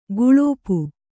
♪ guḷōpu